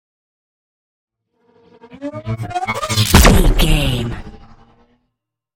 Pass by fast vehicle engine explosion sci fi
Sound Effects
Fast
futuristic
intense